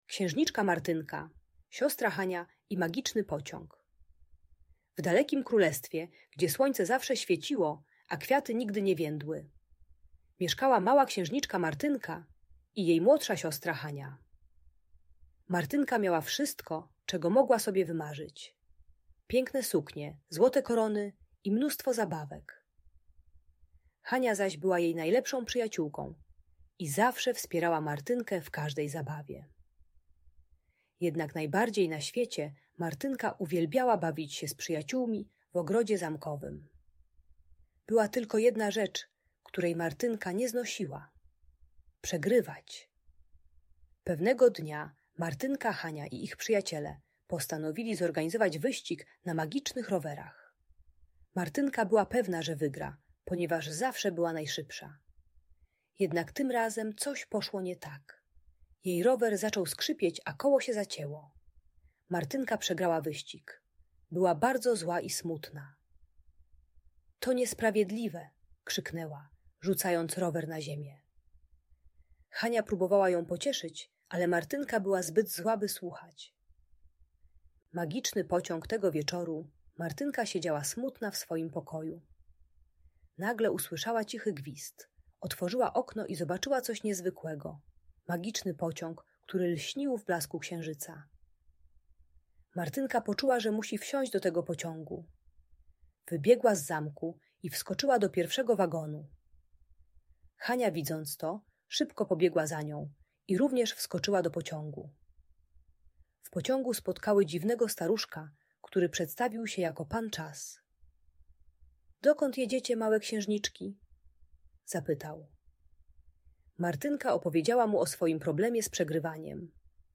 Opowieść o Księżniczce Martynce i Magicznym Pociągu - Audiobajka